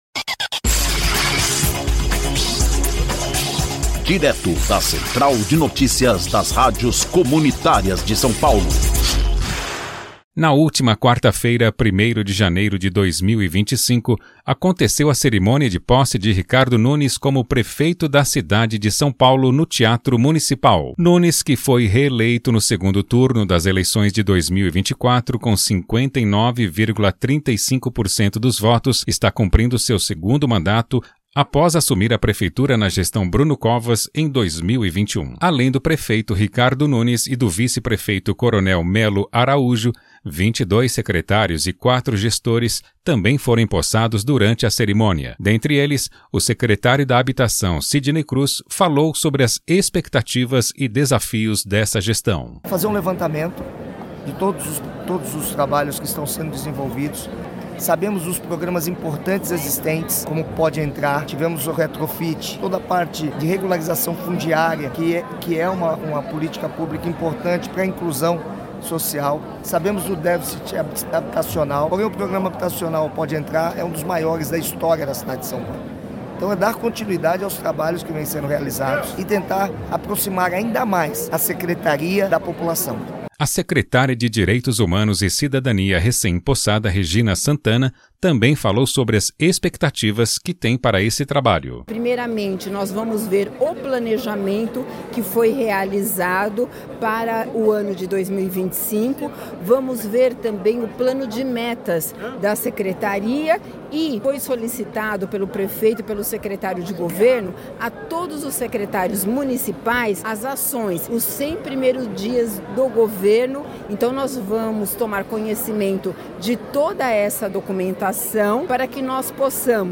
INFORMATIVO: CERIMÔNIA DE POSSE CELEBRA SEGUNDO MANDATO DE RICARDO NUNES.
Prefeito da cidade de São Paulo participou de cerimônia junto ao time de secretários. Na última quarta-feira, 1º de janeiro de 2025, aconteceu a cerimônia de posse de Ricardo Nunes como prefeito da cidade de São Paulo.